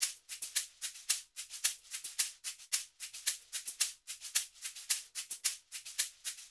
WVD SHAKER 2.wav